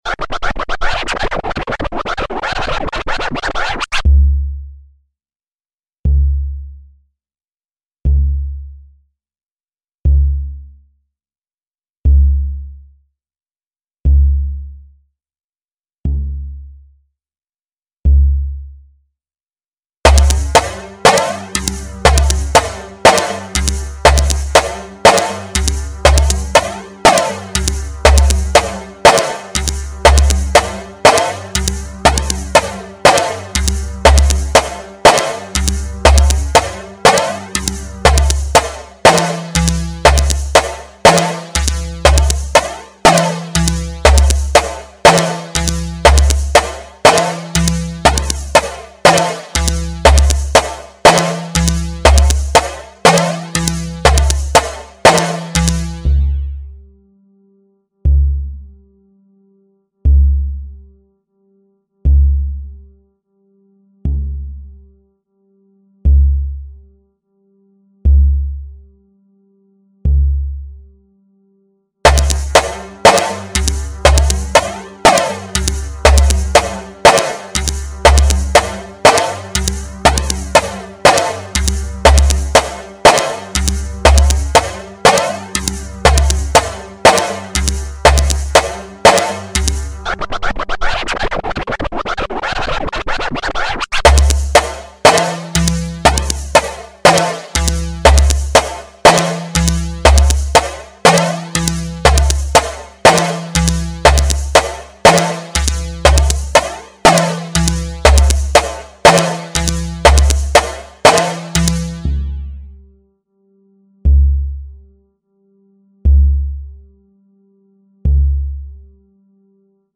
Nur der Beat, und keine Runde.
Wieder 5 Minuten Beat da kann man nichts bewerten, wird ja nichts gesagt?!